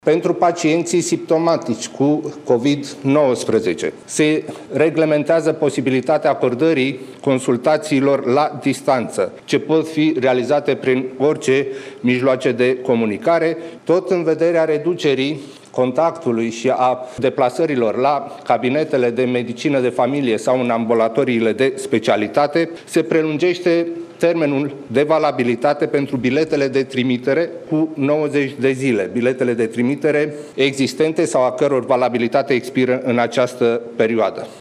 Nu mai este obligatorie transmiterea în platforma informatică a asigurărilor de sănătate în termen de trei zile, a anunțat Ionel Dancă, șeful Cancelariei premierului: